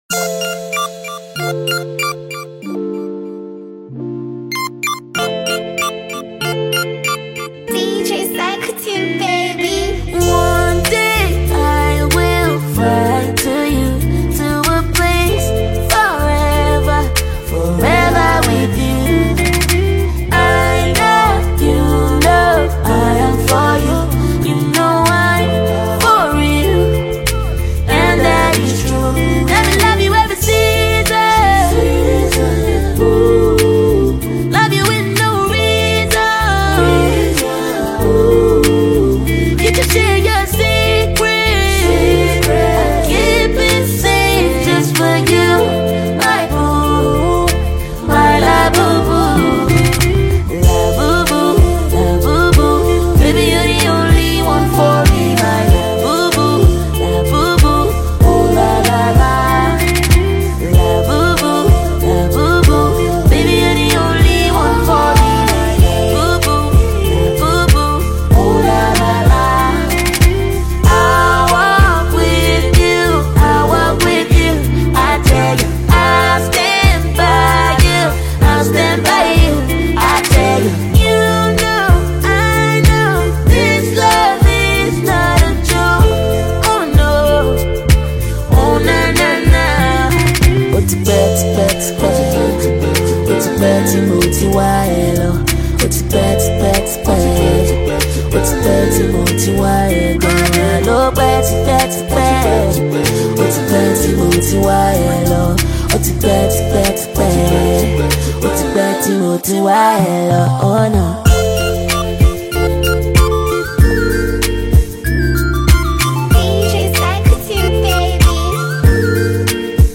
sweet blend of energy, rhythm, and playful vibes